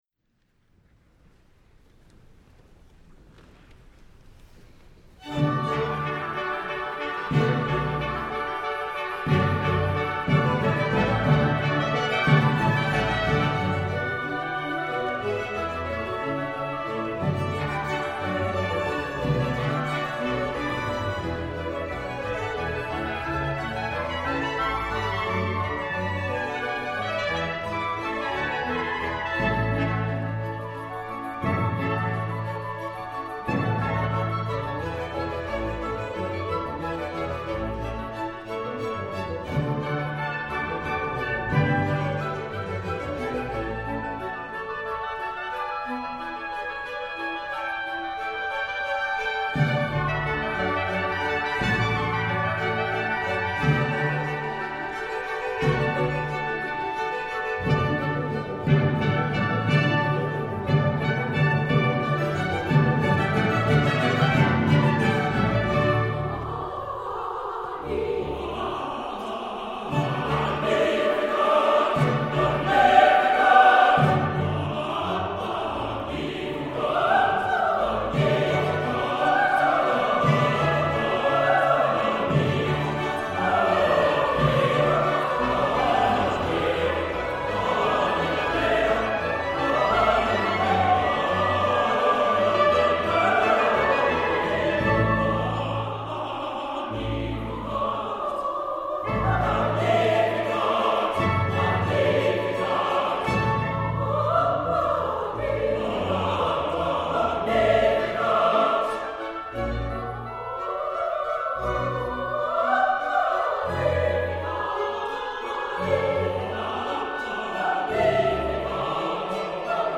The singers in this performance are represented by